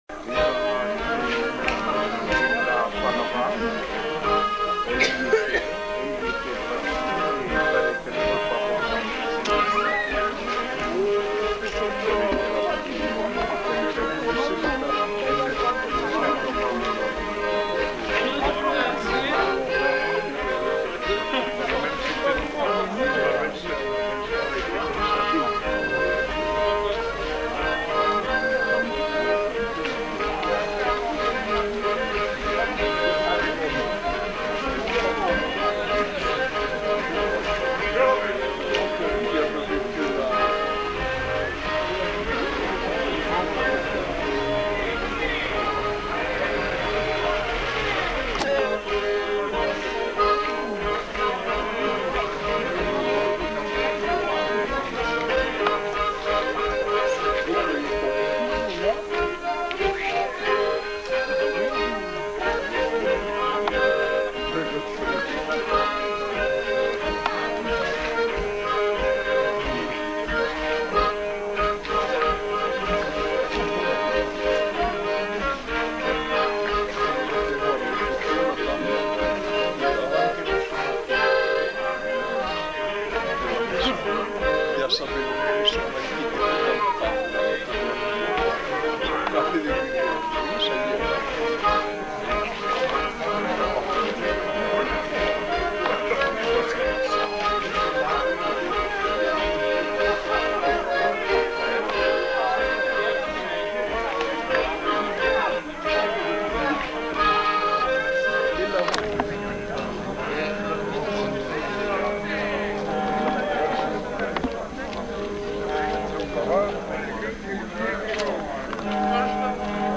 Aire culturelle : Savès
Lieu : Espaon
Genre : morceau instrumental
Instrument de musique : accordéon diatonique
Danse : polka piquée